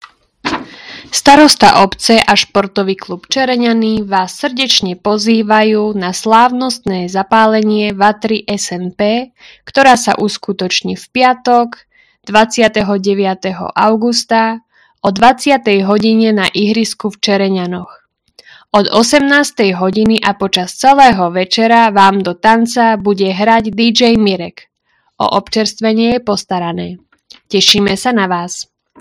Hlásenie obecného rozhlasu – Vatra SNP 29.08.2025